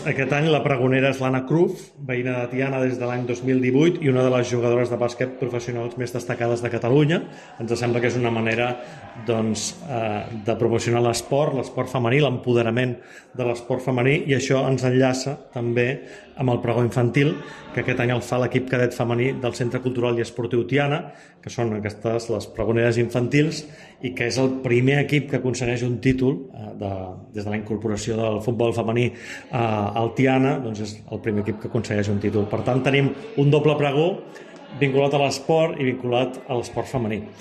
El tret de sortida el donaran l’equip cadet femení del CCE Tiana i la jugadora de bàsquet Anna Cruz en un doble pregó -l’infantil i el previ al Toc d’Inici, respectivament- vinculat a l’esport femení. Així ho ha explicat l’alcalde de Tiana, Isaac Salvatierra: